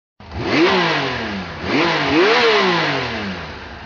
Klingelton Motor Bike Sound
Kategorien Soundeffekte
motor-bike-sound.mp3